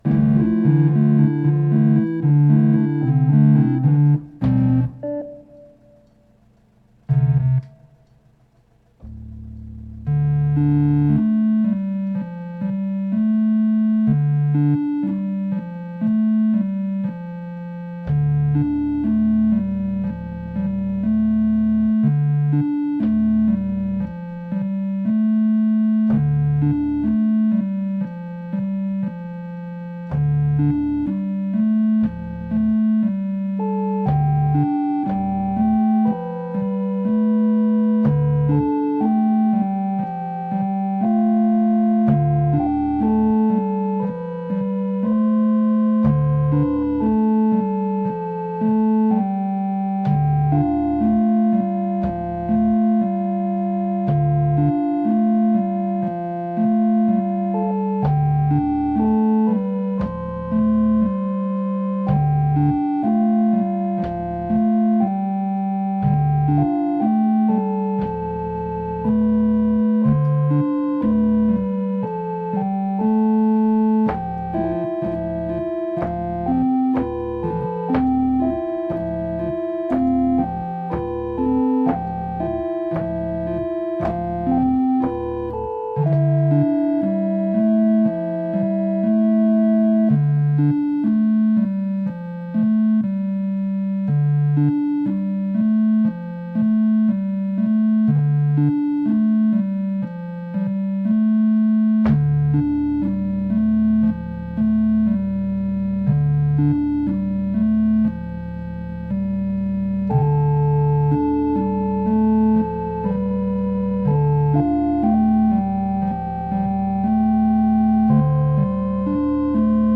Day8_Organ_DooDeeDoo.mp3